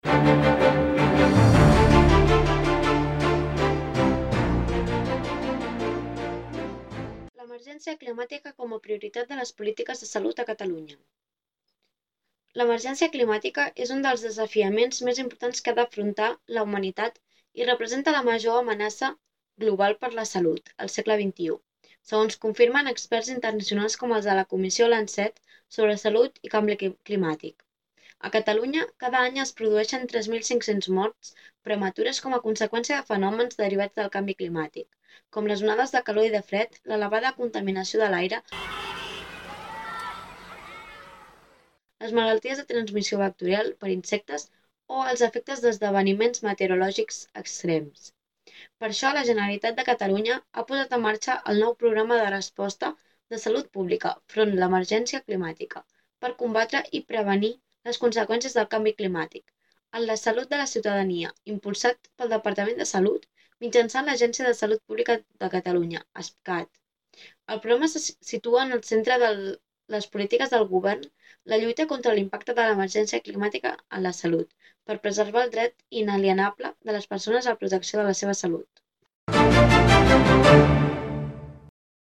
Notícia canvi climàtic
Noticia-sobre-canvi-climàtic.mp3